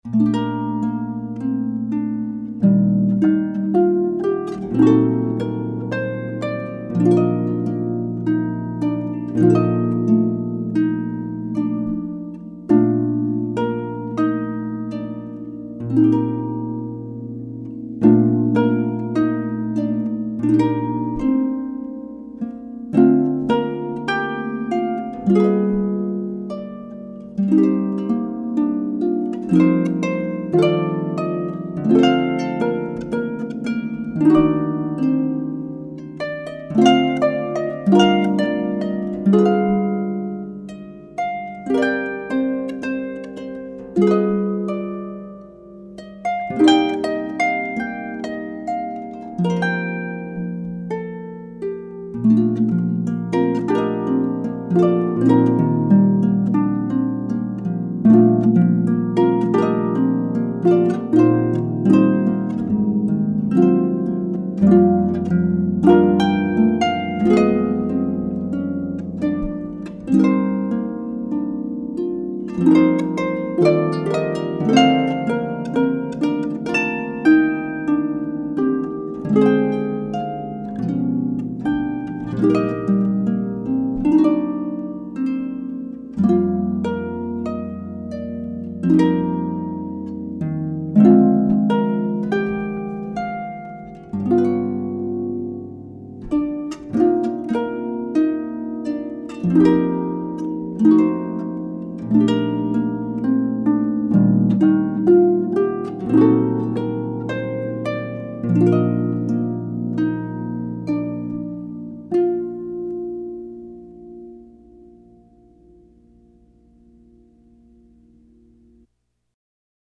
Harpist 2
harp2-10.mp3